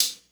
Boom-Bap Hat CL 59.wav